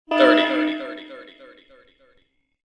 SelfDestructThirty.wav